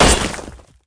attack.mp3